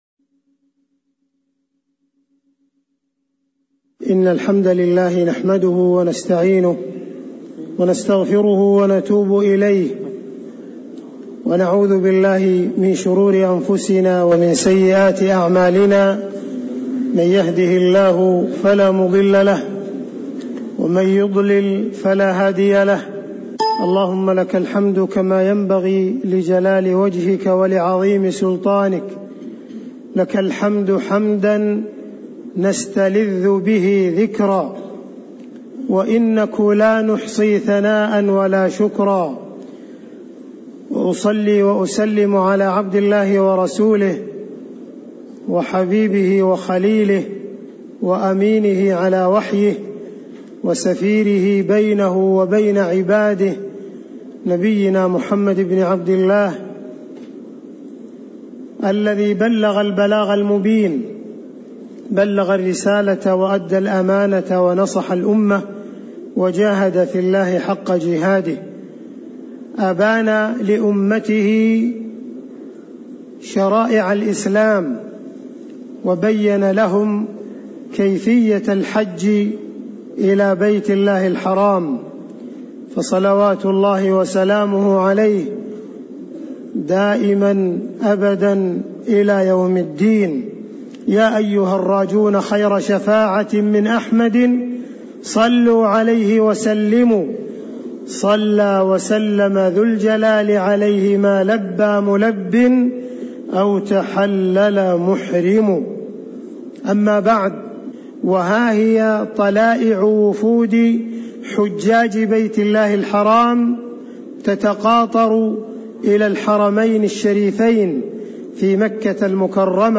محاضرة هديه صلى الله عليه وسلم في حجه وعمرته
المكان: المسجد النبوي